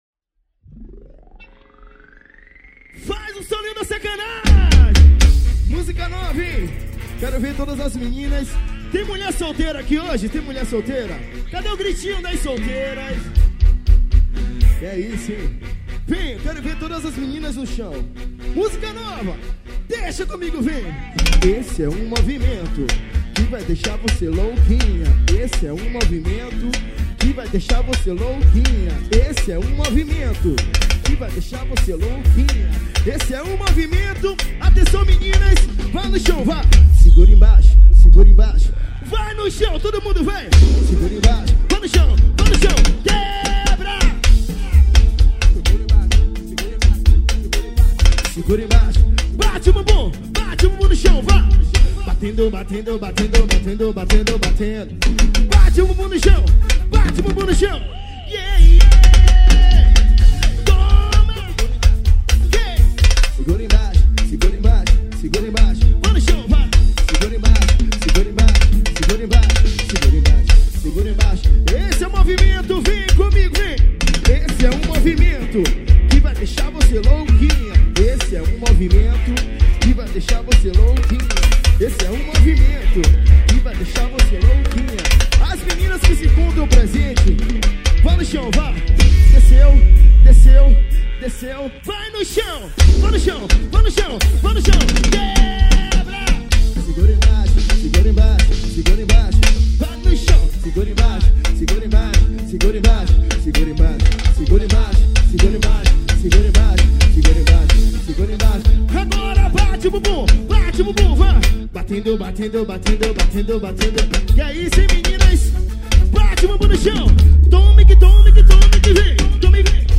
BAIXE AQUI O MELHOR DO PAGODÃO